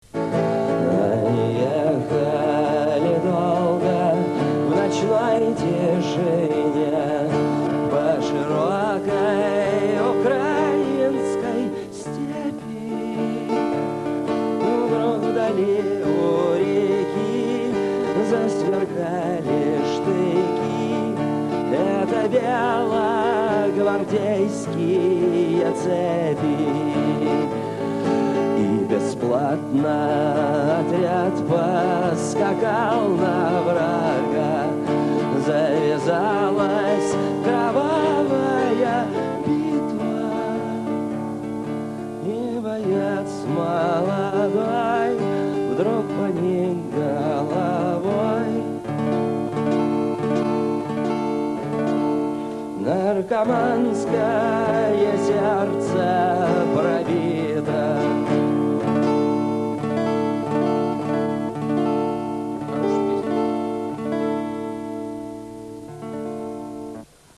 Кавер-версии